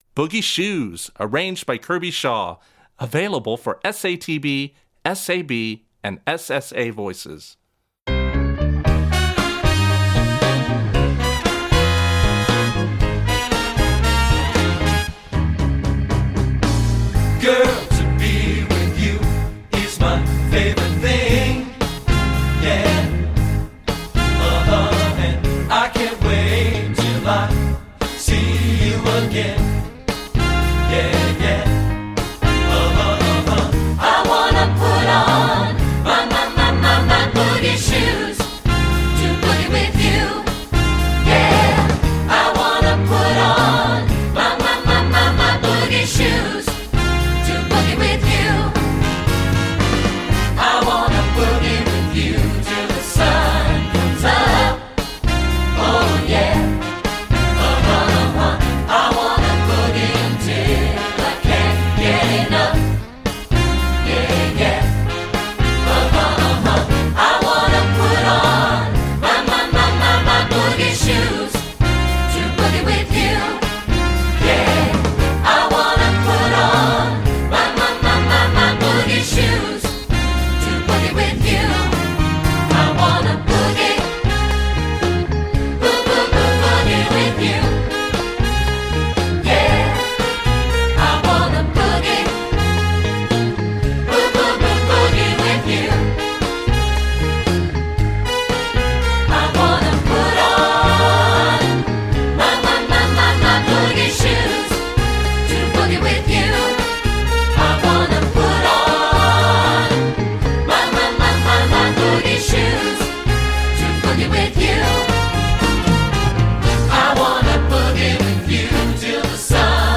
Voicing: Instrumental Parts Level